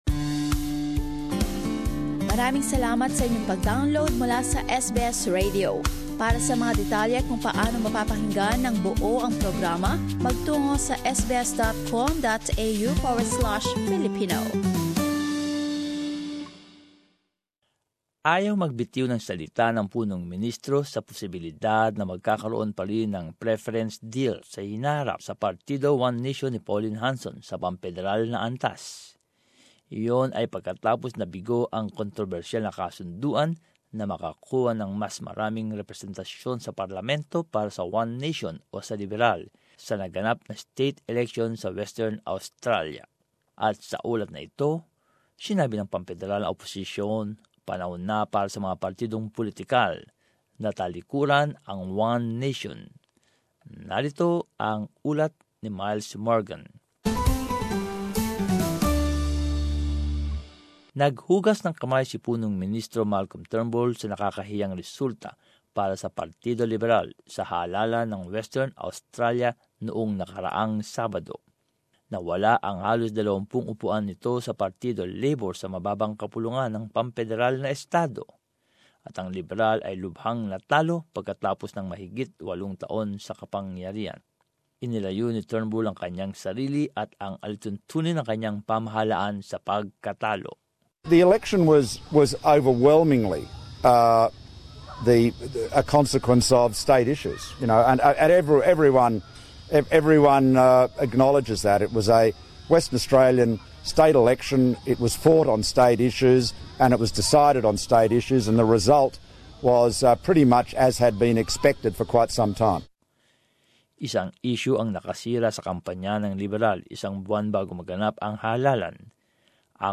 SKIP ADVERTISEMENT And as this report shows, the federal opposition says it's time for political parties to turn their backs on One Nation.